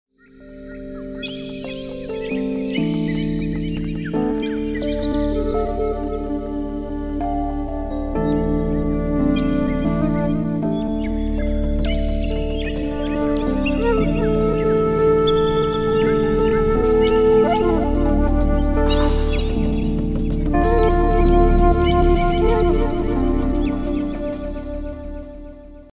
Index of /phonetones/unzipped/Vodafone/Smart-First-6/alarms
Birds_Signature.ogg